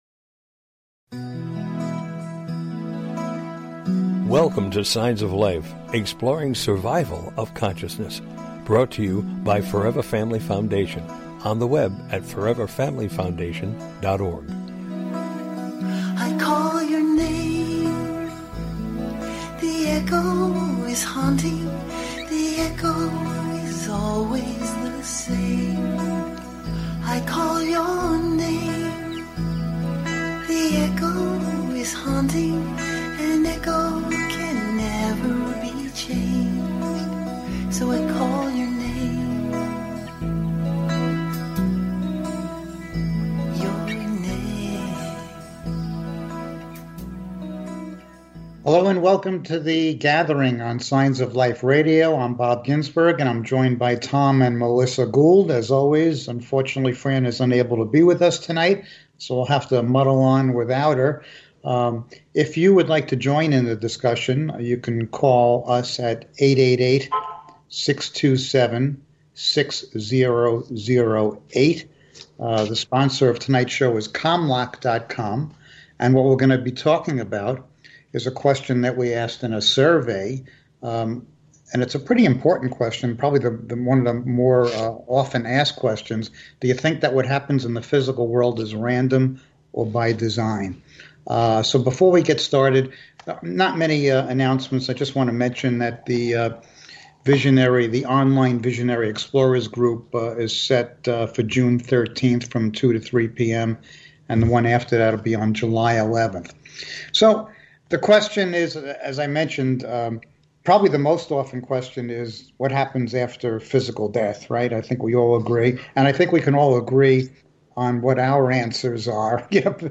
The Gathering: A Discussion about After Life Communication